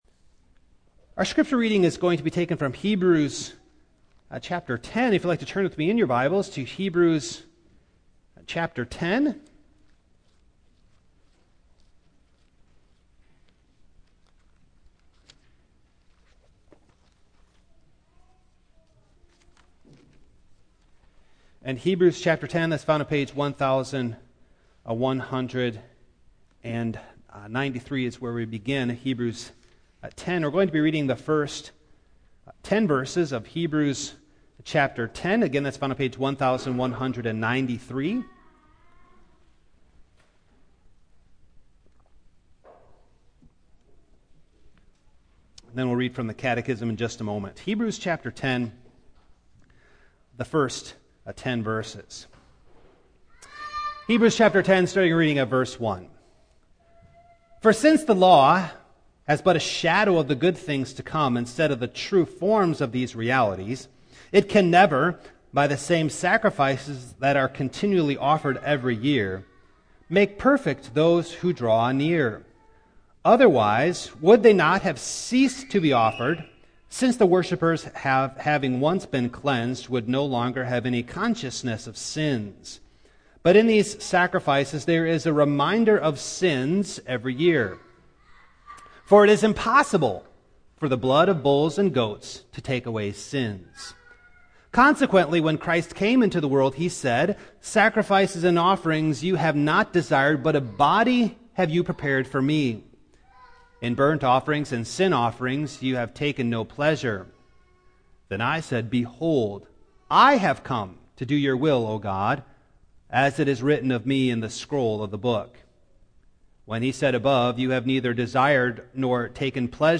2022-23 Passage: Heb. 10:1-10 Service Type: Morning Download Files Notes « What Does God Command…But Also Provide?